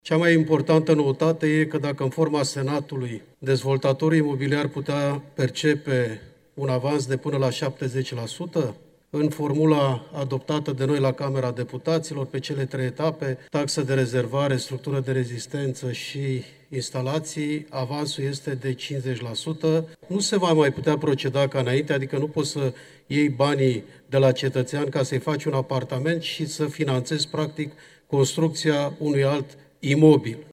Florin Roman, deputat PNL: „Nu se va mai putea proceda ca înainte, adică nu poți să iei banii de la cetățean ca să-i faci un apartament și să finanțezi practic construcția unui alt imobil”